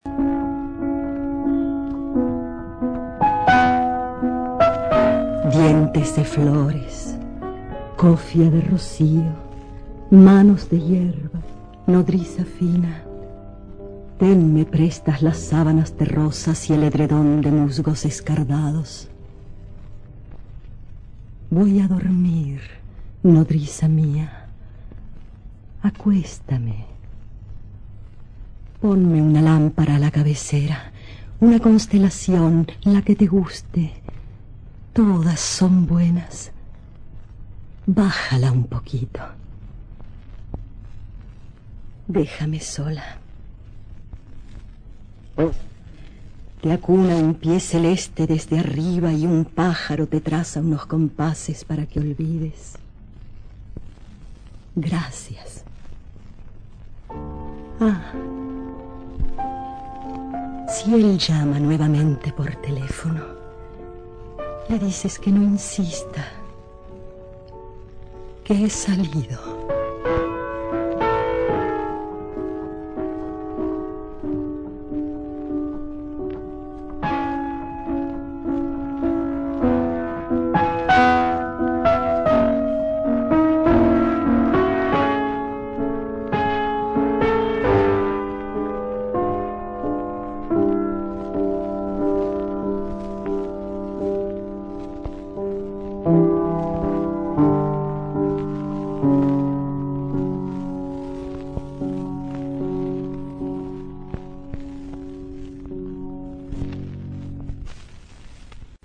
Голос: Мария Роза Гальо (María Rosa Gallo) и Делия Гарсес (Delia Garcés).